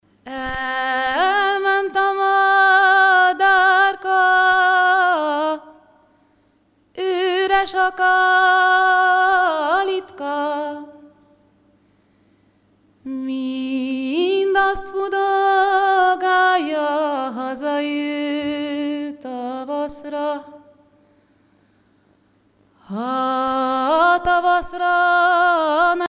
Concert a Brugges Festival, Belgique
Pièce musicale inédite